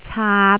男籃 aam
晚餐 aan
生硬 aang